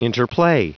Prononciation du mot interplay en anglais (fichier audio)